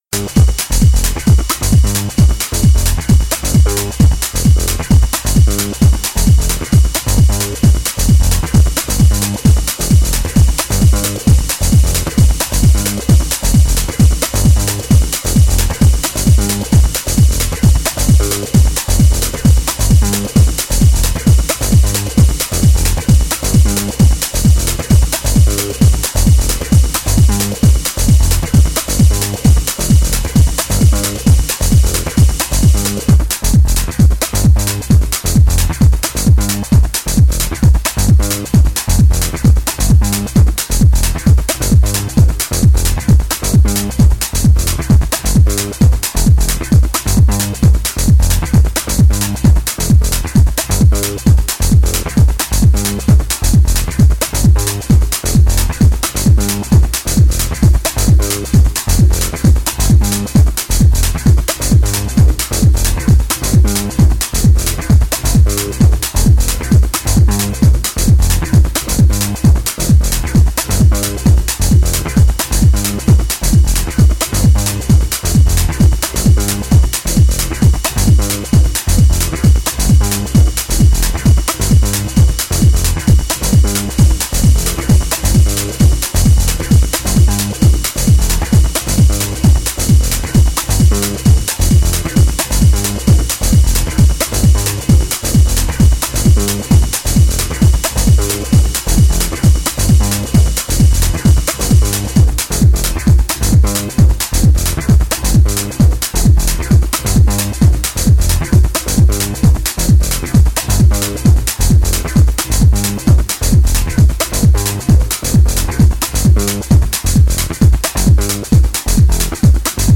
Filed under: Techno/House